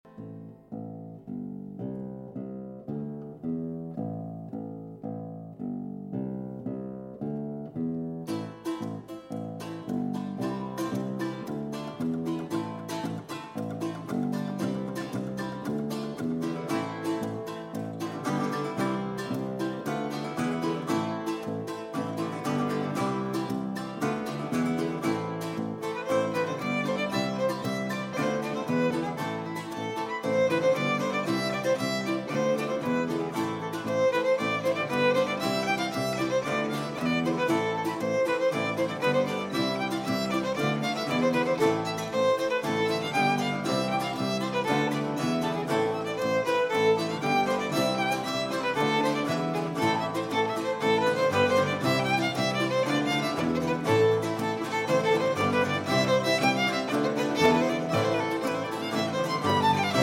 Baroque Ensemble